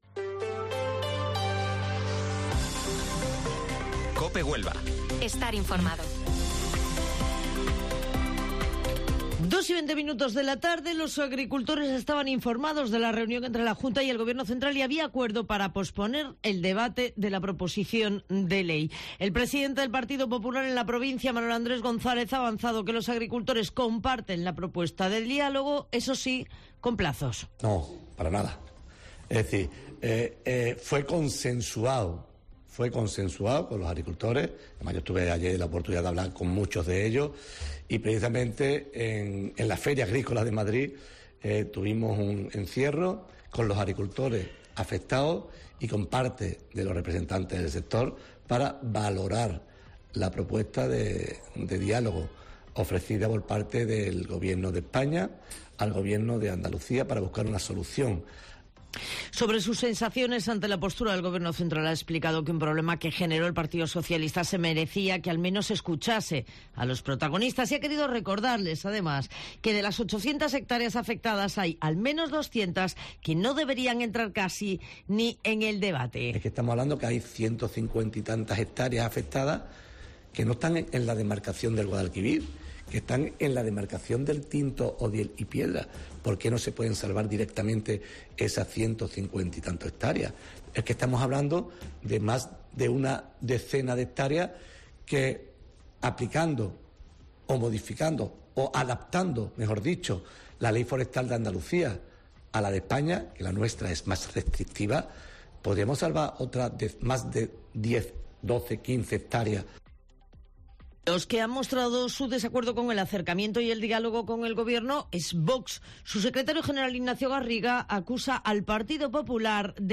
Informativo Mediodía COPE 5 de octubre